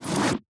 Close Inventory Bag A.wav